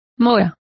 Complete with pronunciation of the translation of default.